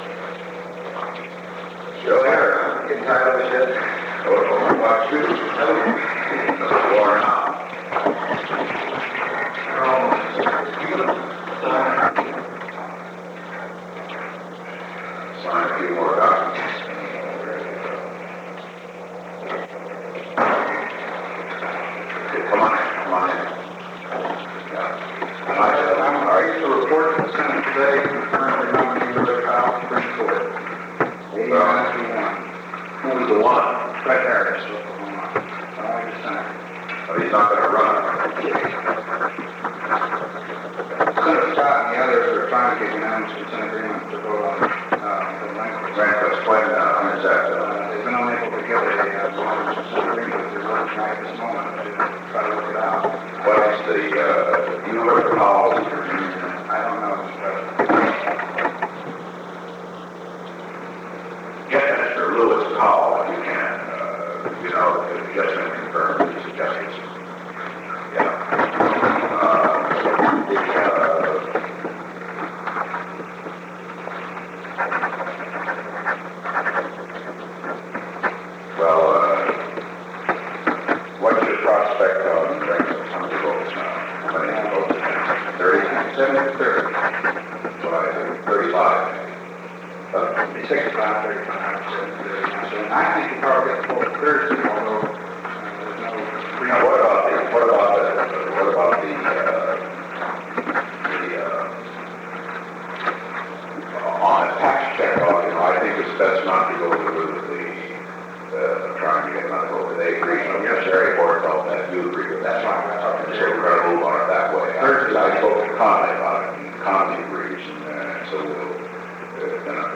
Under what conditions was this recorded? The Oval Office taping system captured this recording, which is known as Conversation 630-022 of the White House Tapes.